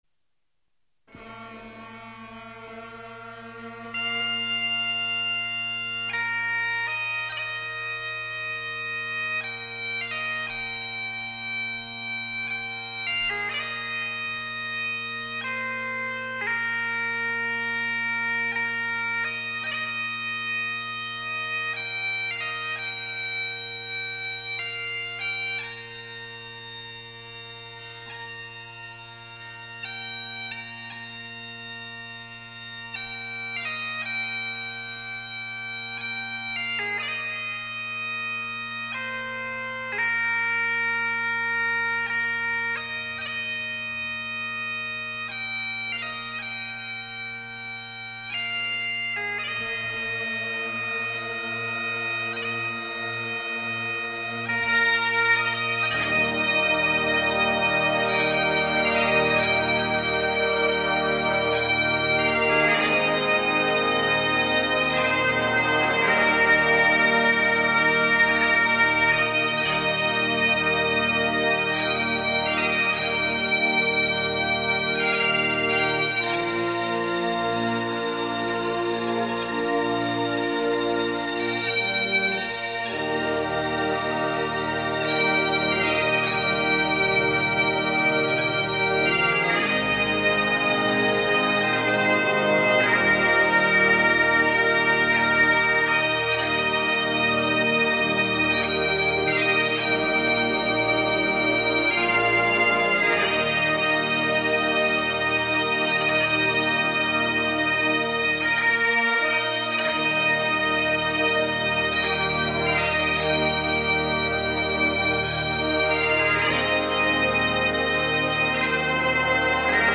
Amazing grace – bagpipe / trompet mp3 :
amazing-grace-bagpipemusic.mp3